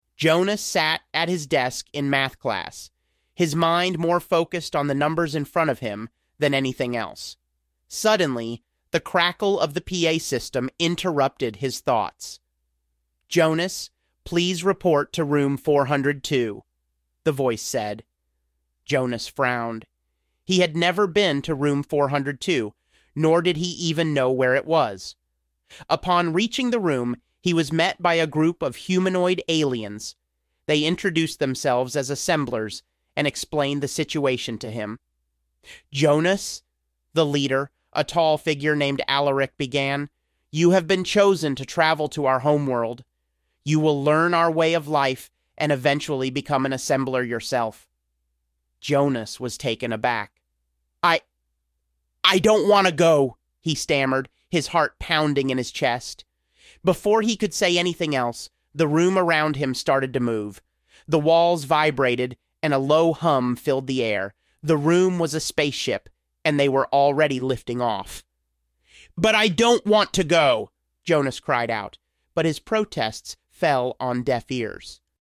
Here's my professionally cloned voice, using the English v2 model, reading the first part of a story generated by GPT4. For some reason they only gave me 1000 characters so it cut off, but still, you can hear the difference.